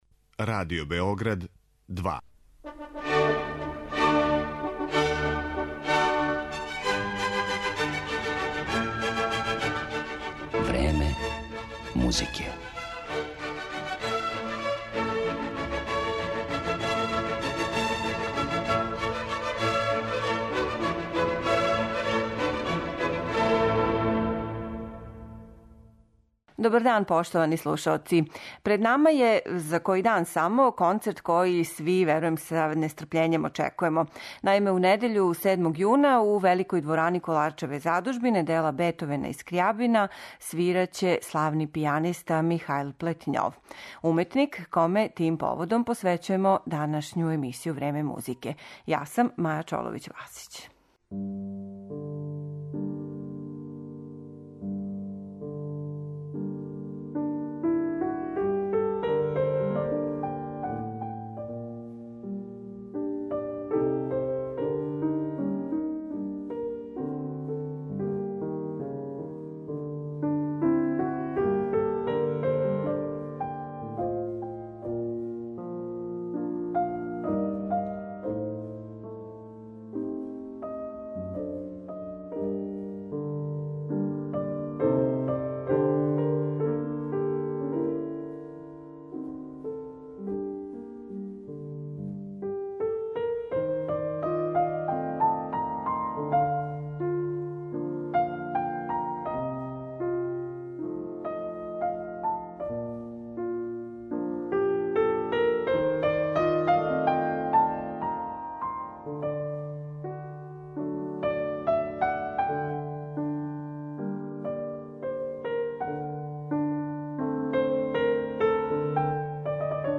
слушаћете дела Шопена, Чајковског, Прокофјева и других у извођењу овог врсног пијанисте.